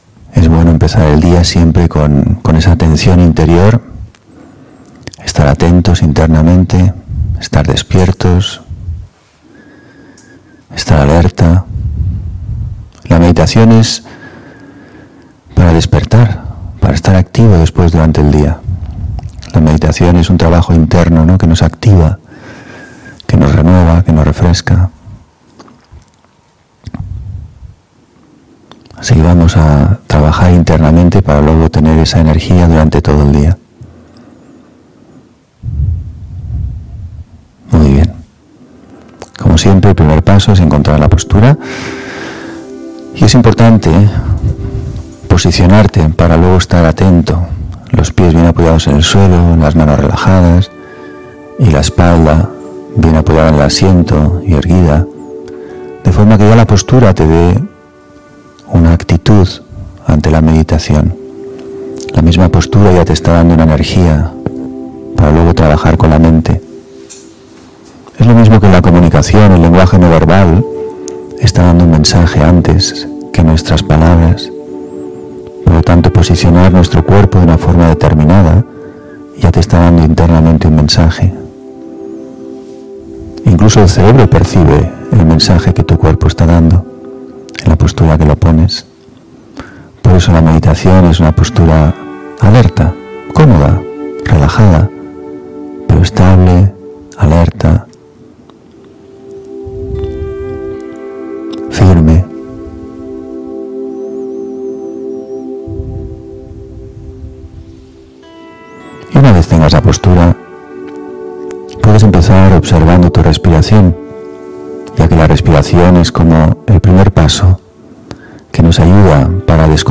meditacion_despertar_conciencia.mp3